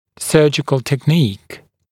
[‘sɜːʤɪkl tek’niːk][‘сё:джикл тэк’ни:к]хирургическая техника, хирургическая методика